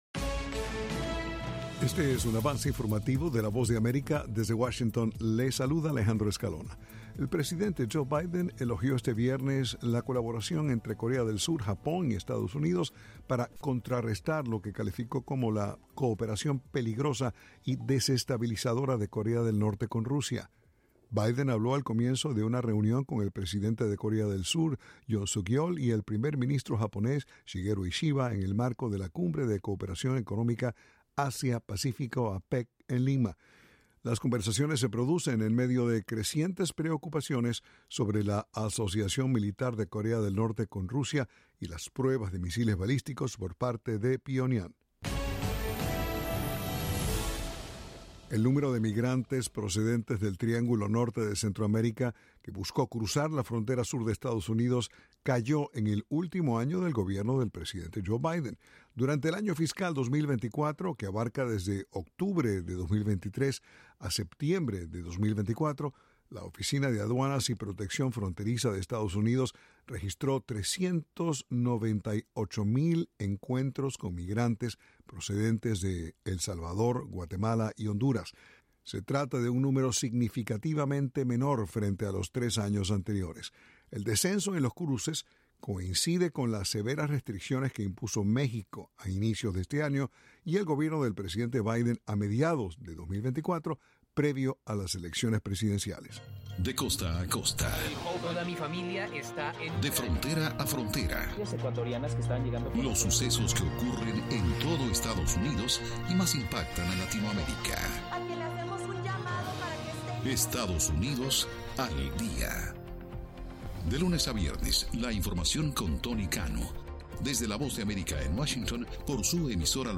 Avance Informativo
El siguiente es un avance informativo de la VOA.